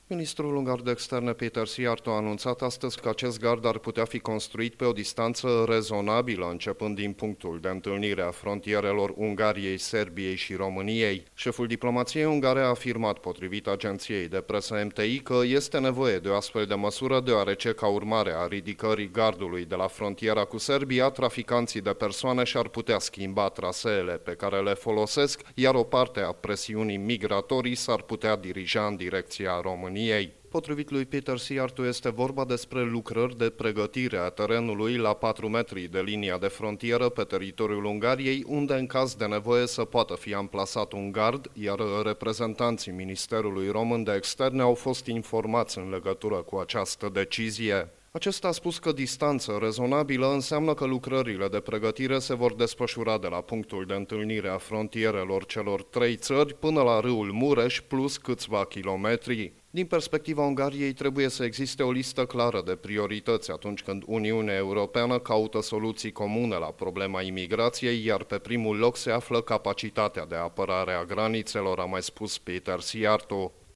De la Budapesta